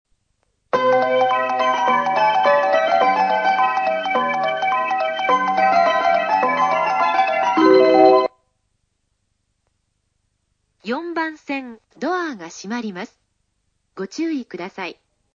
この駅は小さい駅ですし、高崎線という路線自体、鳴らす路線ではないので２コーラス目には滅多にはいりませんし、途中切りも多いです。
４番線発車メロディー 曲は「Bellの響き」です。